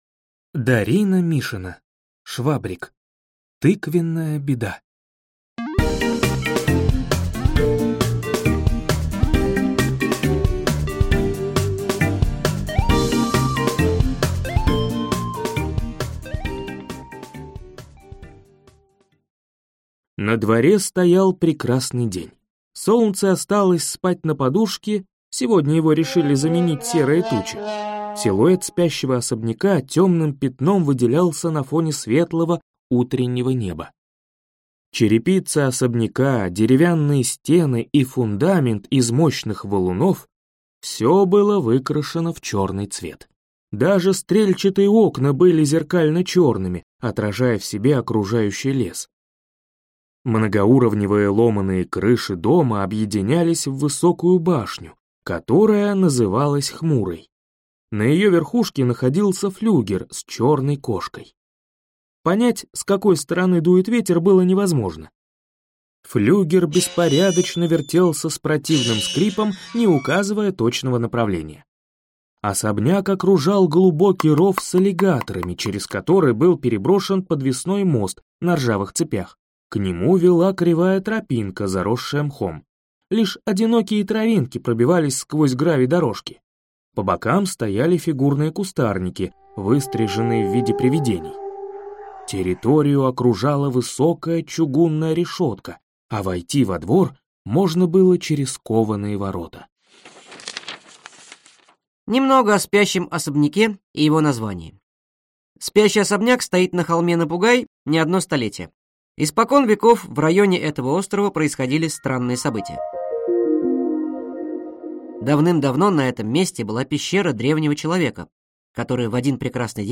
Аудиокнига Швабрик. Тыквенная беда | Библиотека аудиокниг